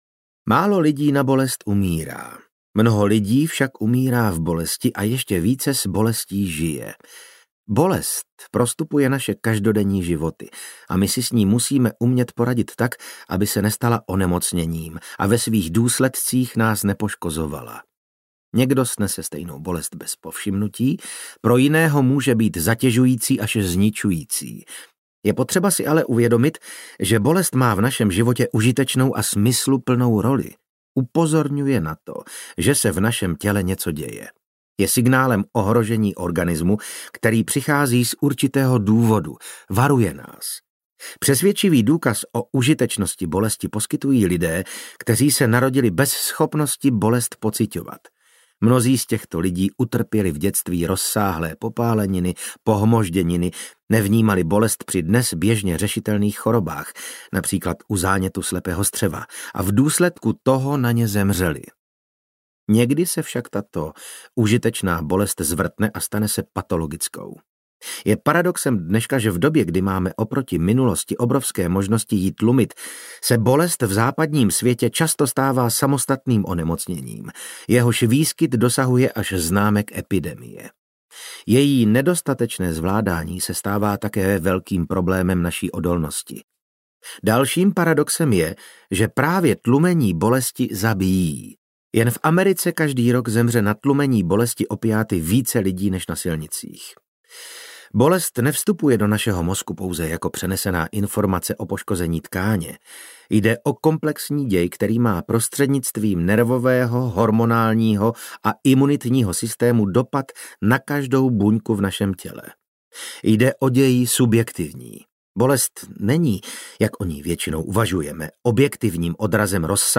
Audiokniha Labyrint bolesti - Pavel Kolář | ProgresGuru
Čte: Vasil Fridrich